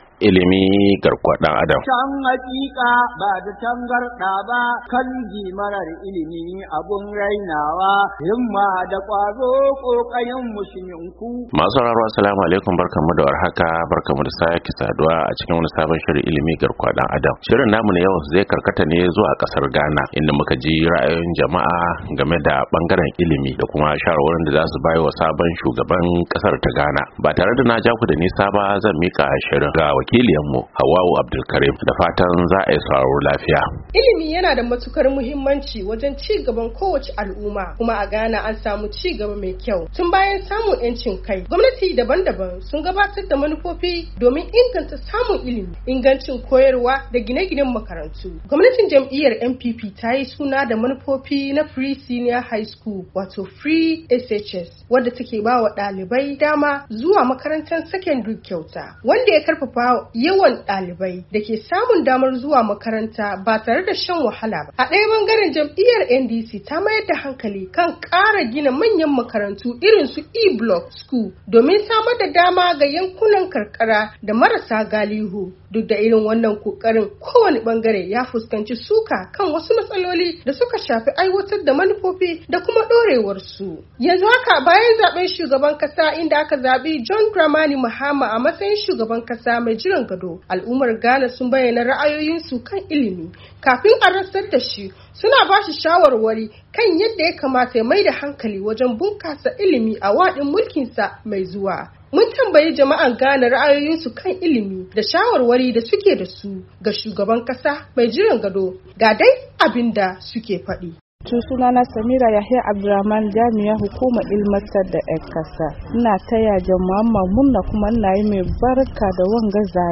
A shirin Ilimi na wannan makon mun tattauna ne da wasu 'yan Ghana bayan zaben shugaban kasar inda suka bayyana shawarwarin su game de bangaren ilimi a kasar.